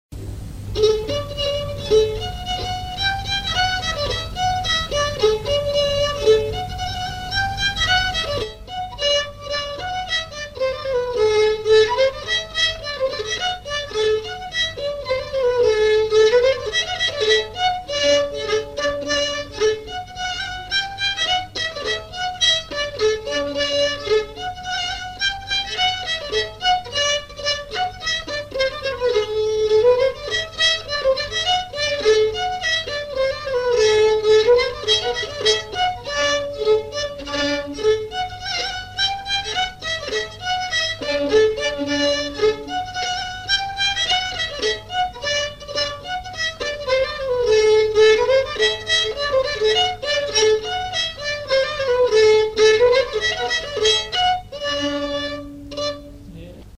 Chants brefs - A danser
danse : polka
enregistrements du Répertoire du violoneux
Pièce musicale inédite